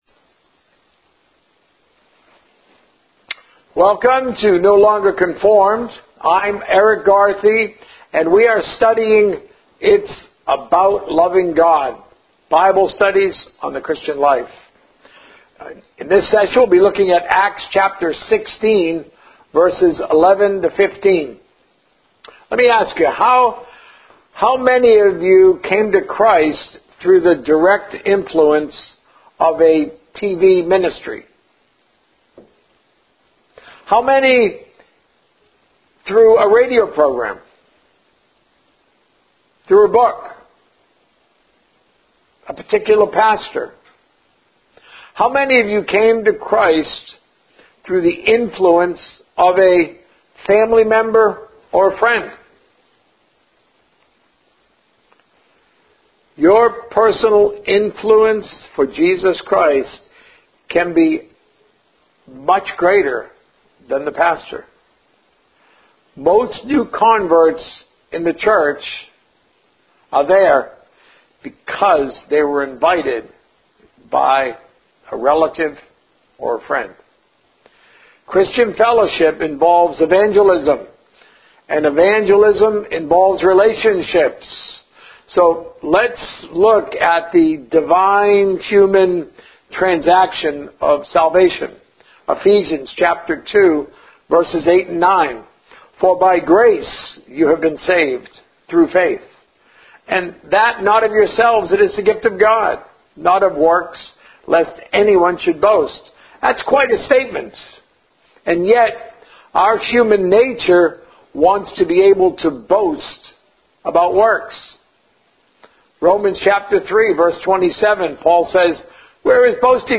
A message from the series "It's About Loving God."